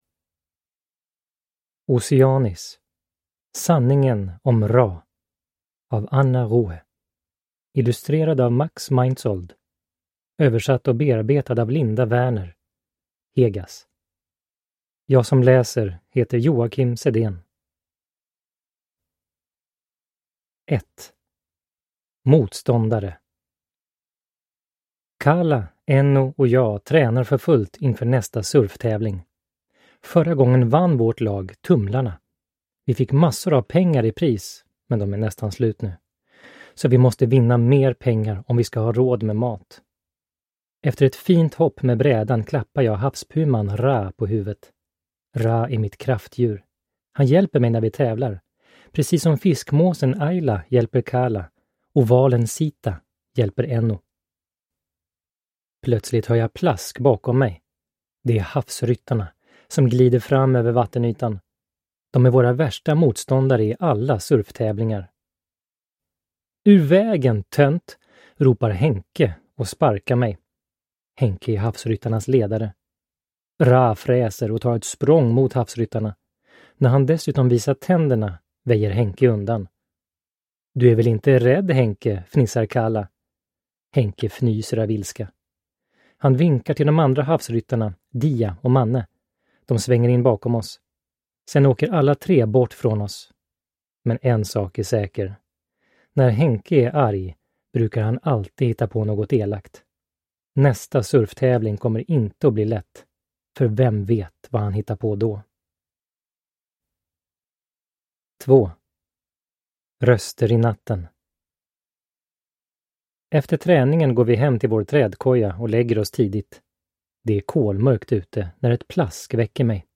Sanningen om Raa (ljudbok) av Anna Ruhe